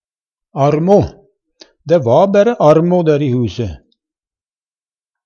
armo - Numedalsmål (en-US)